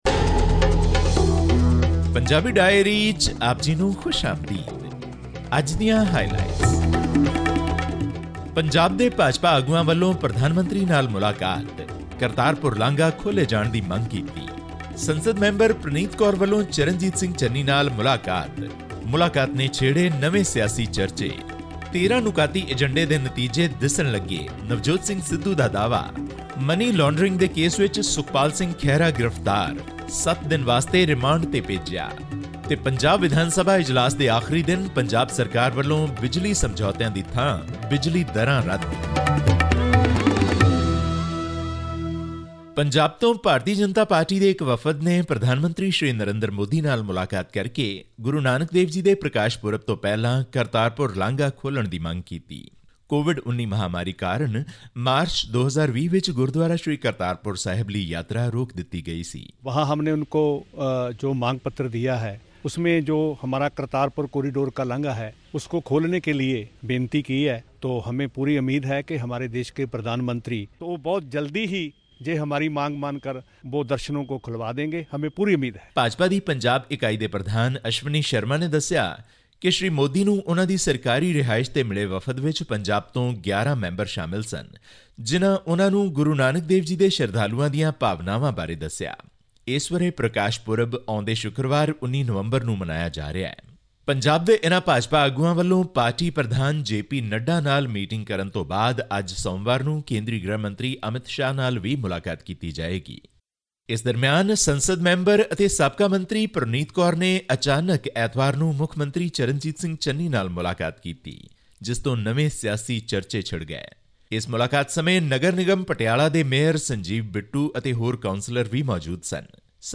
This and more in our weekly news update from Punjab.